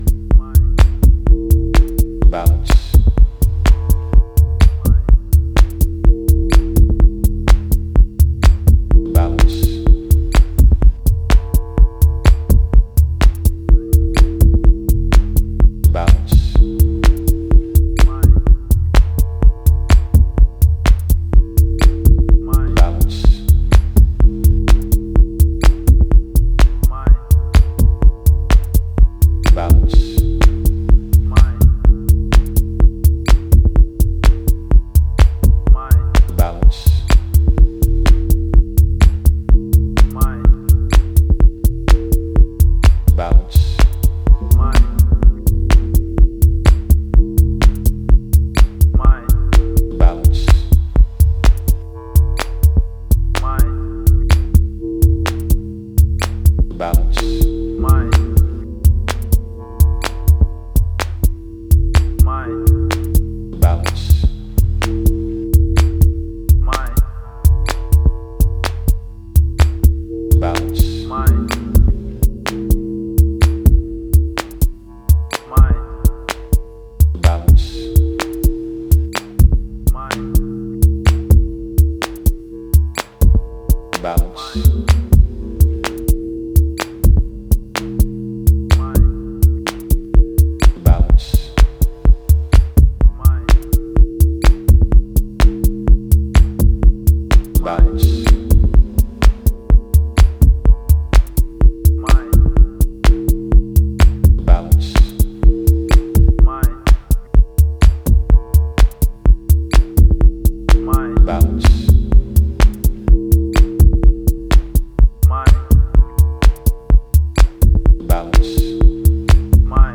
minimal techno